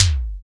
Index of /90_sSampleCDs/Roland - Rhythm Section/KIK_Electronic/KIK_Analog K1
KIK EZ E.K3.wav